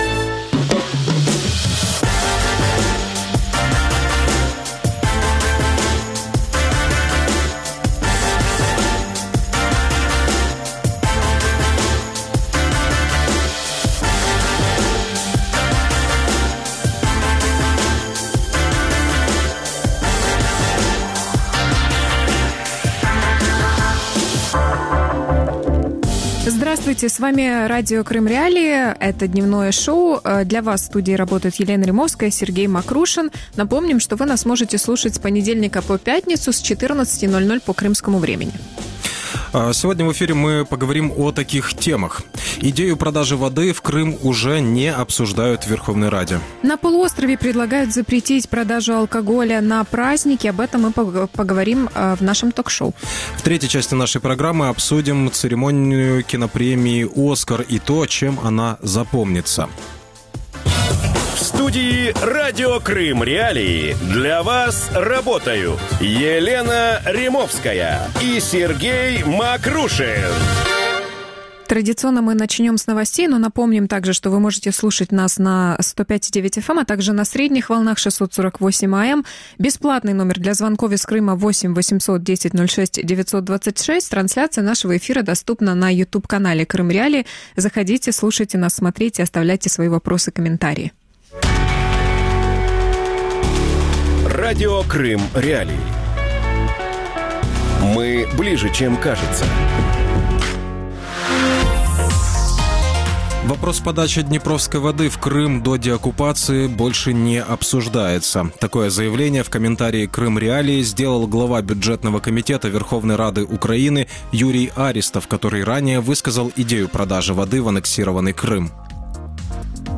В Крыму запретят продавать алкоголь в праздники? | Дневное ток-шоу